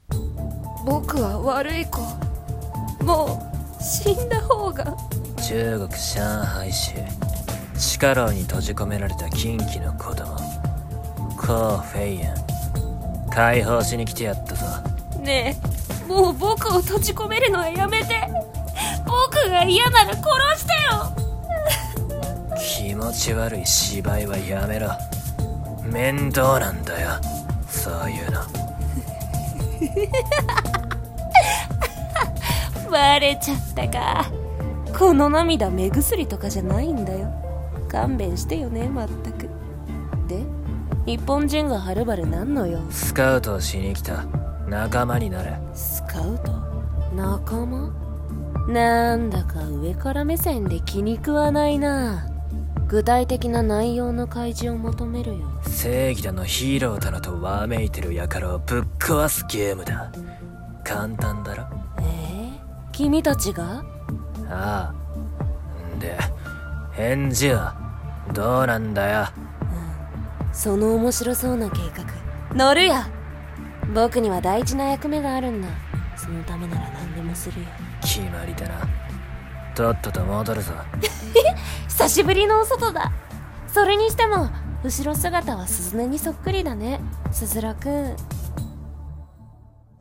声劇】禁忌の子供